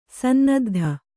♪ sannaddha